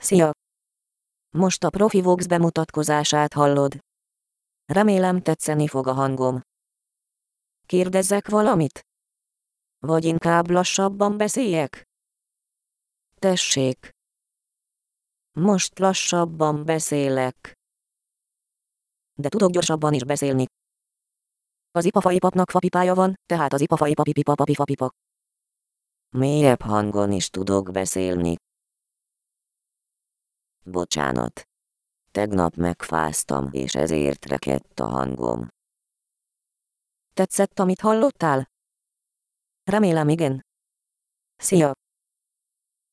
I've looked through free and demo TTS implementations but found only one demo that did that typical intonation of the Hungarian yes-no question well.
As you can see, there is a rising-falling intonation at the end of the word.
Here is the TTS demo mentioned above: